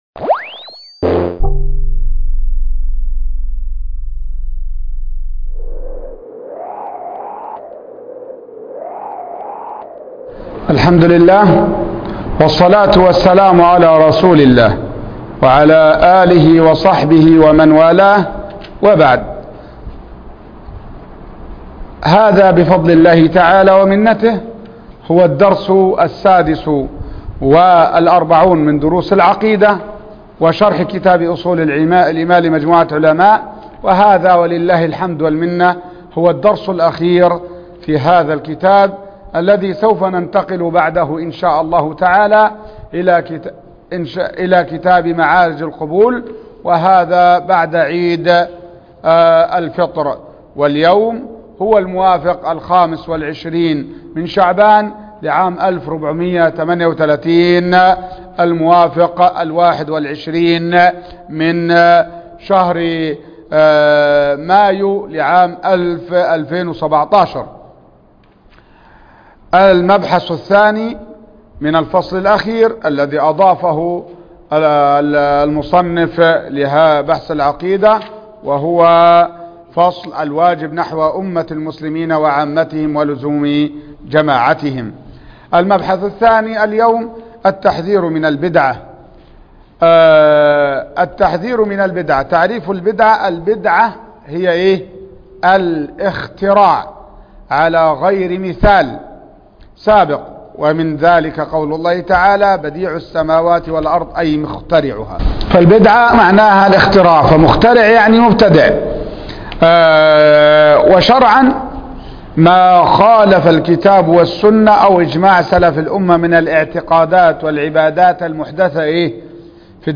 الدرس السادس والأربعون ( أصول الإيمان فى ضوء الكتاب والسنة )